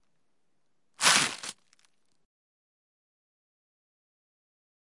南极洲 " 南极洲半岛的丹科岛溅起的冰山
描述：在南极洲半岛的丹科岛，用猎枪式话筒（Schoeps）录制了一只在水中飞溅的冰球，
标签： 冰山 飞溅 现场 录音
声道立体声